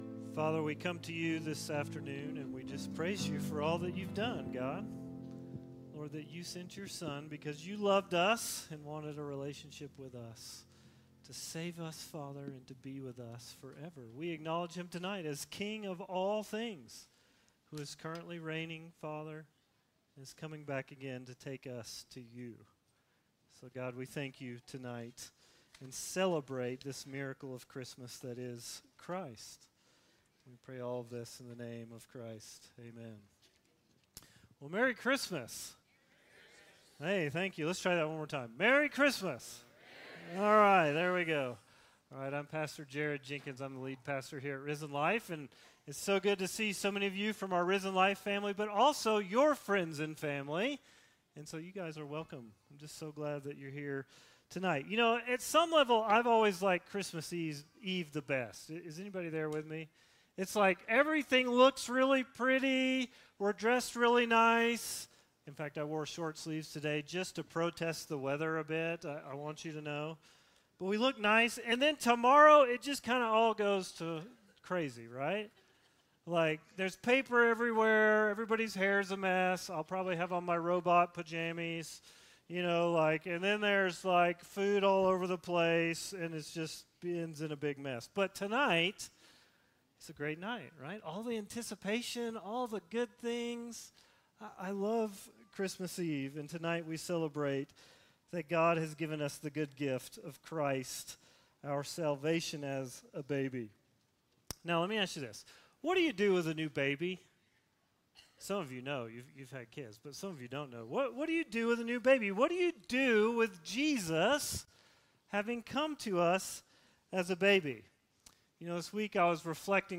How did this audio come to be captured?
Join us as we celebrate the birth of our savior Jesus with our annual Christmas Eve service.